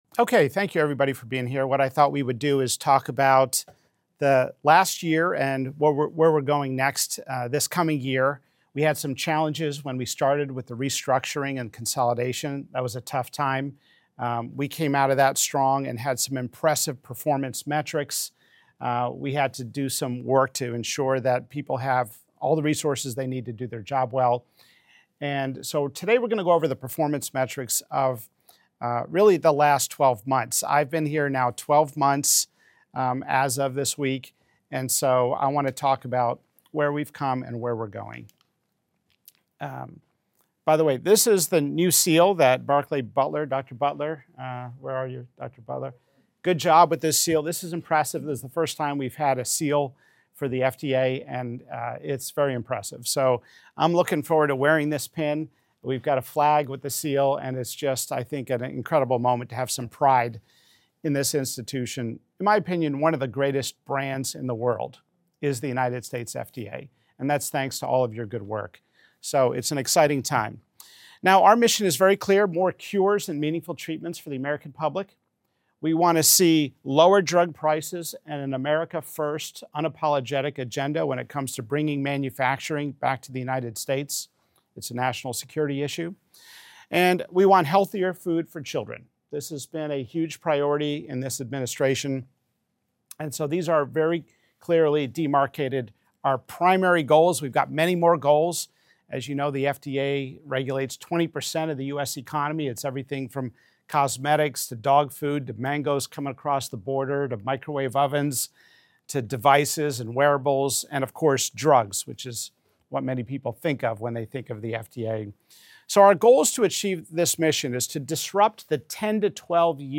Commissioner Makary and other members of FDA leadership, as well as guests, engage in timely and frank discussions to bring you an inside look at the current happenings at the FDA.